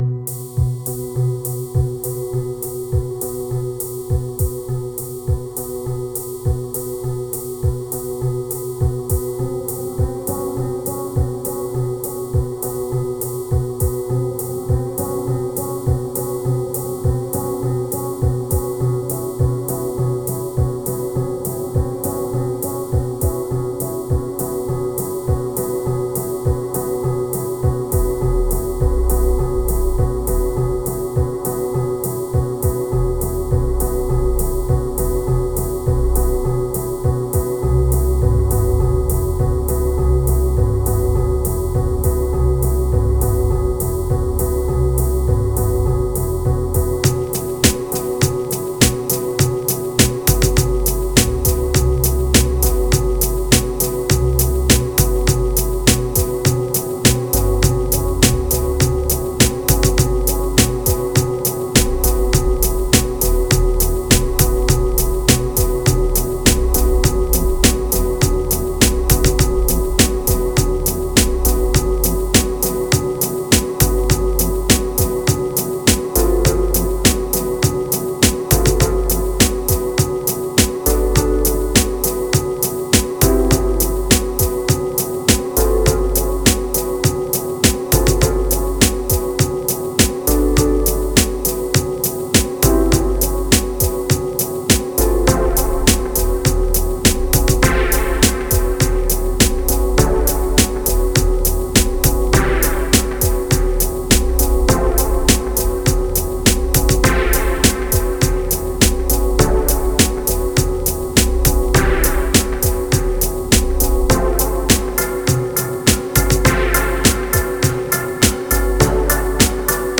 Prepare your subwoofer or your deep headz.
Ambient Kicks Moods Spring Rejoice Times Instant Bliss Hope